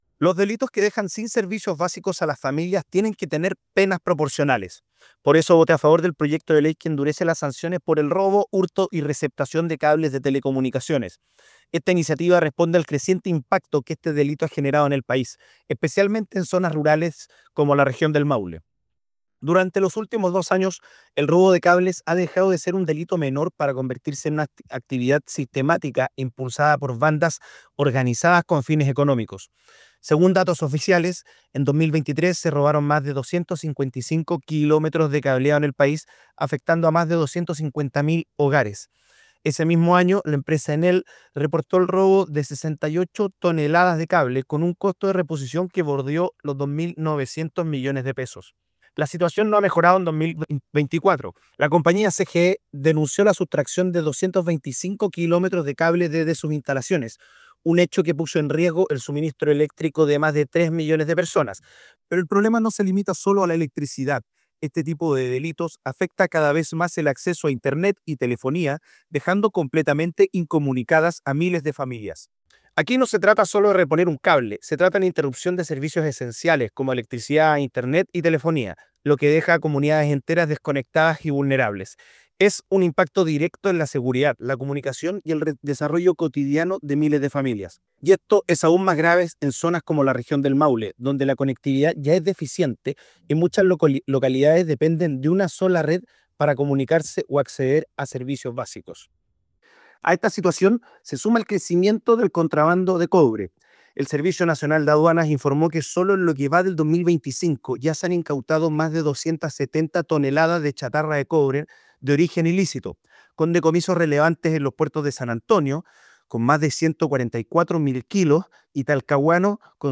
cuña-Moreno-ley-robo-de-cables-.mp3